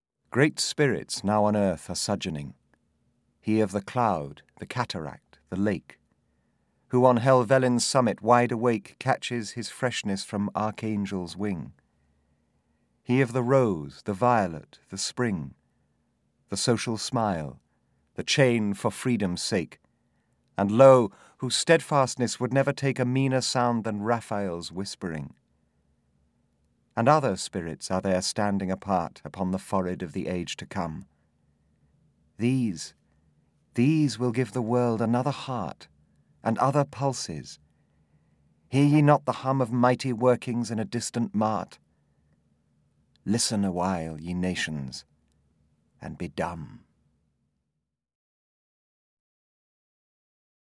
The Great Poets – John Keats (EN) audiokniha
Ukázka z knihy
• InterpretSamuel West, Michael Sheen